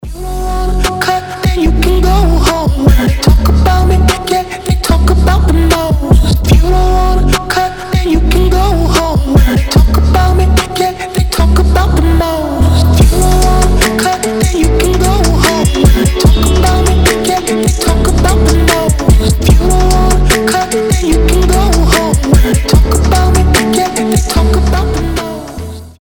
мужской голос
басы
приятные
медленные
кайфовые
Chill Trap